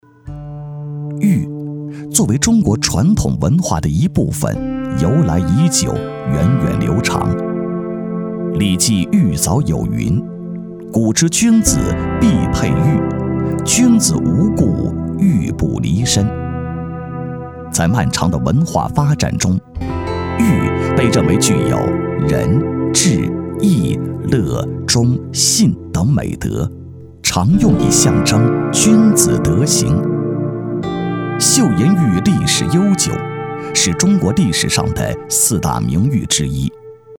纪录片男111号（岫岩玉）
娓娓道来 文化历史
大气浑厚，磁性稳重男音，擅长讲解纪录片，舌尖，党建专题题材。作品：岫岩玉。